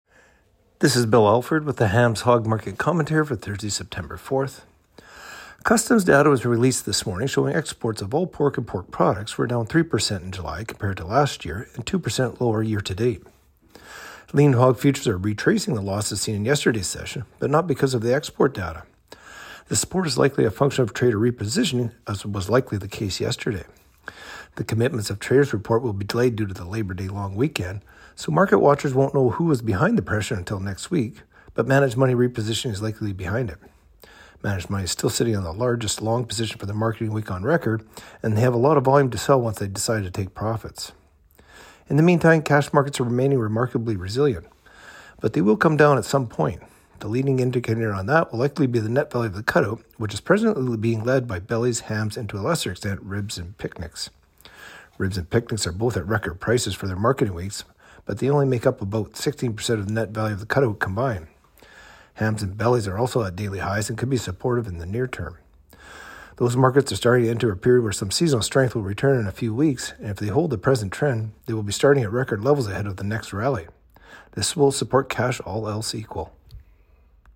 Hog-Market-Commentary-Sep.-4-25.mp3